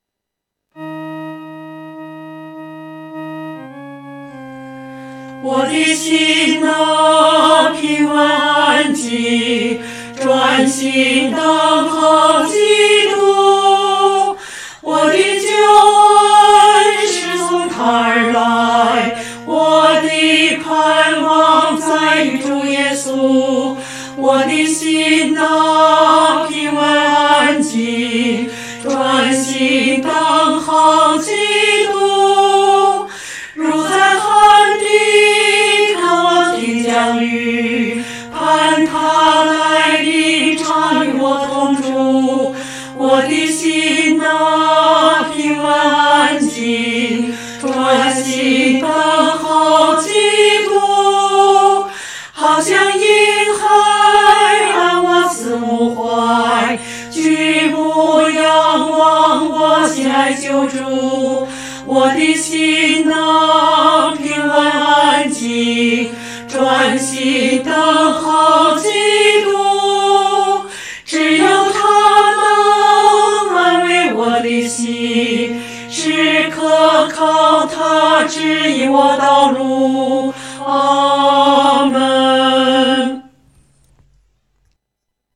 合唱
女高
诗班在二次创作这首诗歌时，要清楚这首诗歌音乐表情是平静、柔和地。